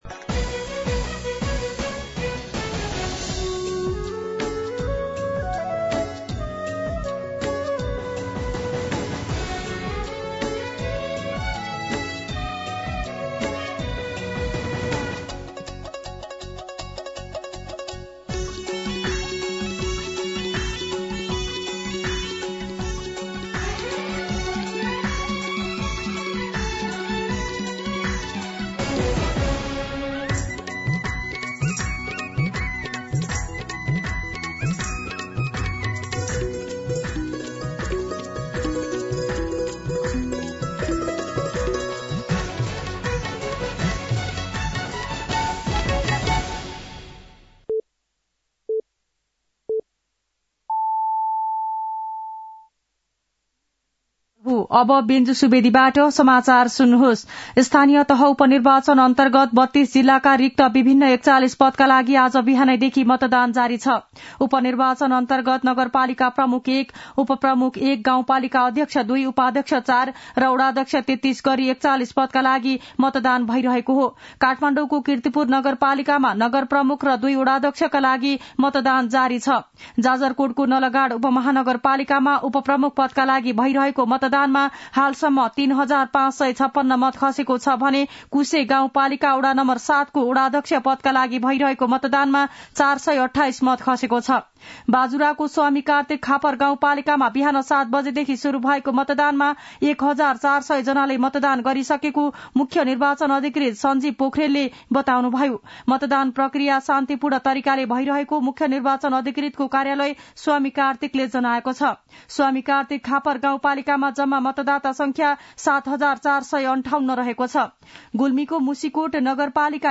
मध्यान्ह १२ बजेको नेपाली समाचार : १८ पुष , २०२६
12-pm-nepali-news-.mp3